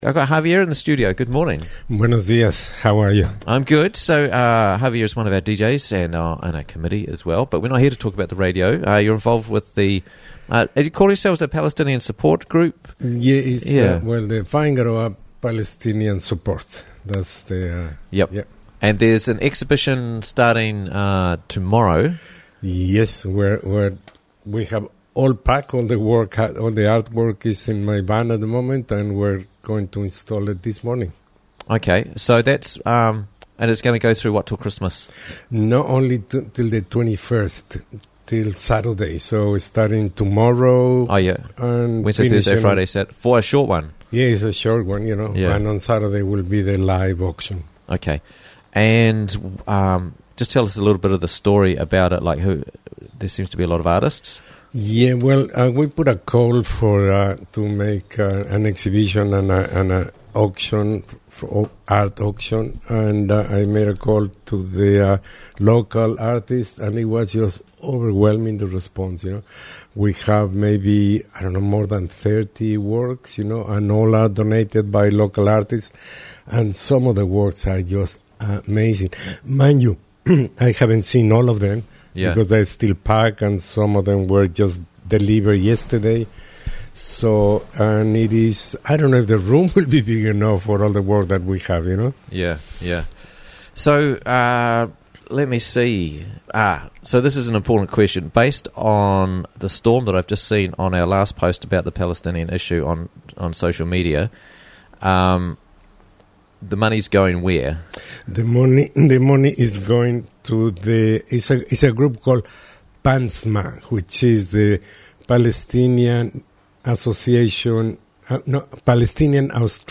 Local Art Fundraiser for Gaza - Interviews from the Raglan Morning Show